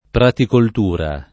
[ pratikolt 2 ra ]